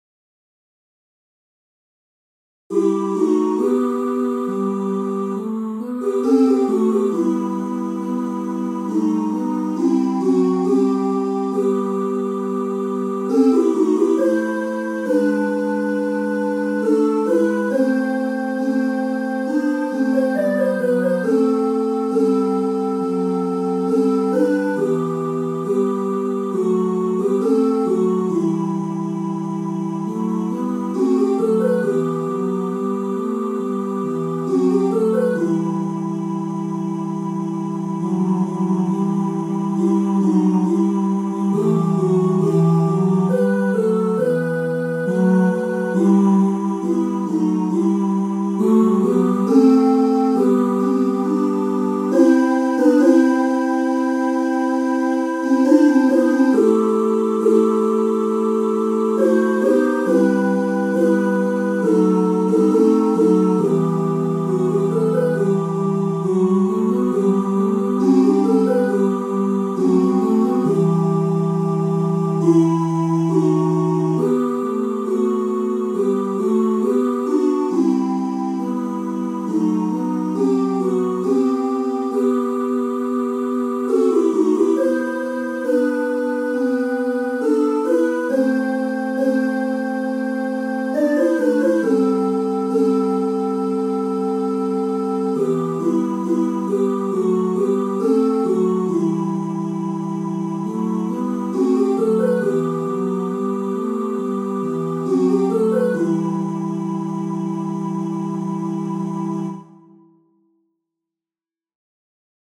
Обработка для хора ( MuseScore, PDF,